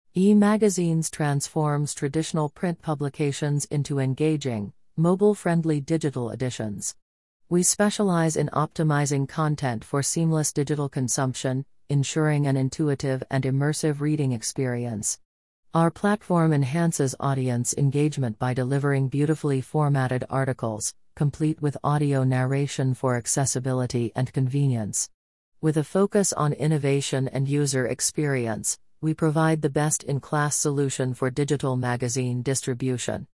We turn text into lifelike speech by leveraging Neural Text-to-Speech systems to create natural-sounding human audio that keeps your subscribers engaged.